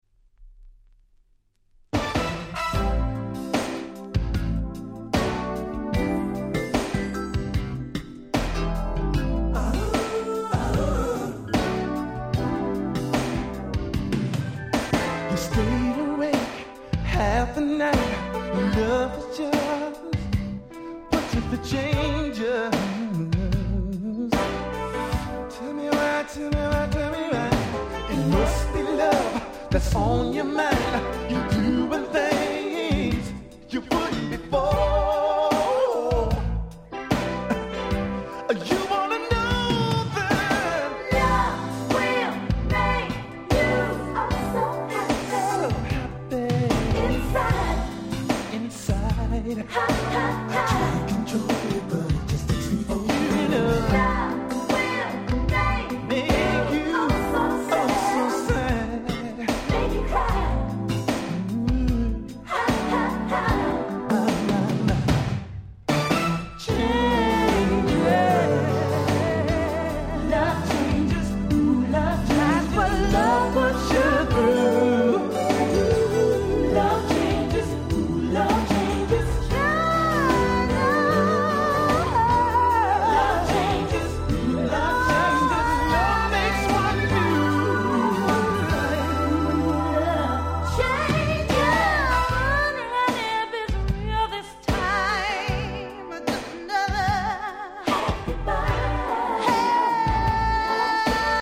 87' Smash Hit R&B LP !!